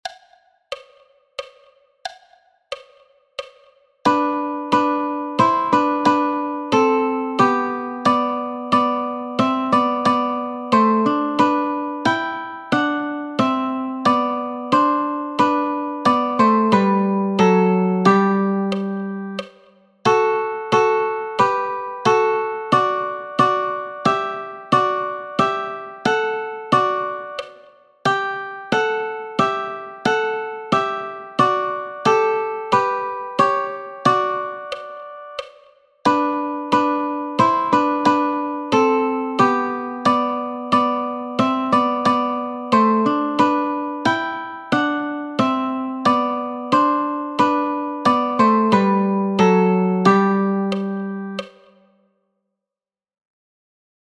als Duette für die Gitarre und die Ukulele